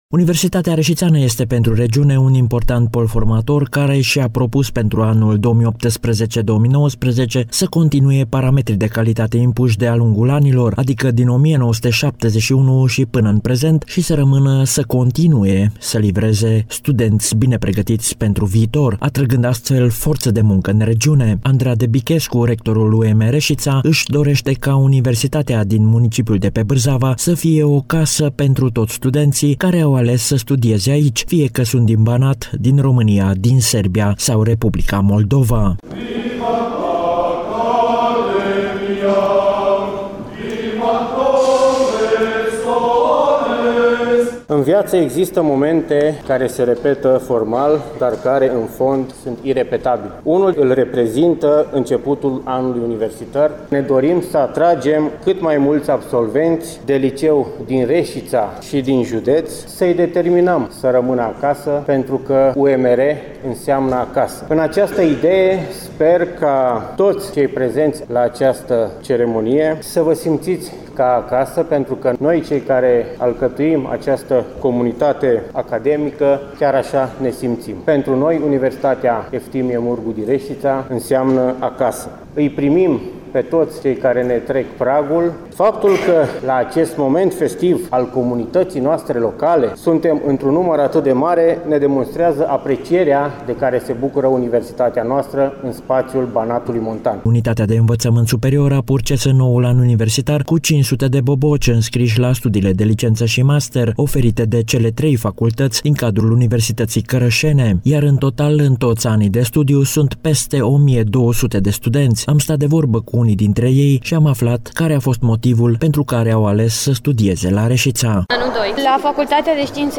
Deschiderea anului de studiu 2018 – 2019 la Universitatea “Eftimie Murgu” din Reşiţa a avut loc luni, 1 octombrie în Aula Magna a instituţiei de învăţământ în prezenţa a sute de studenţi, cadre didactice, părinţi şi invitaţi.